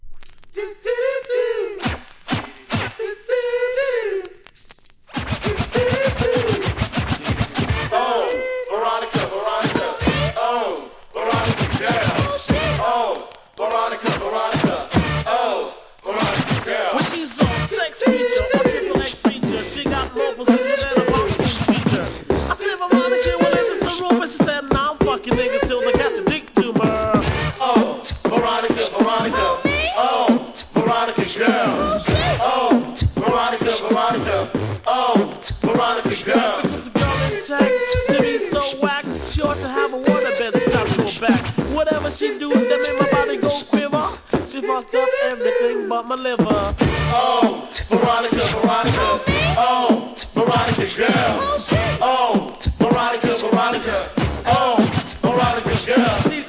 HIP HOP/R&B
b/wはヒューマンビートボックスにイナタイスクラッチ！！